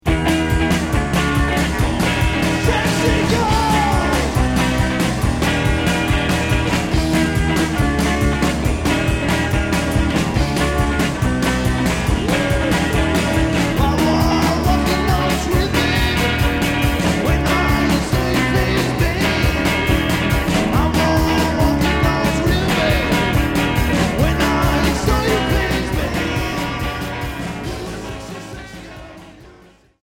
Psychobilly Unique 45t retour à l'accueil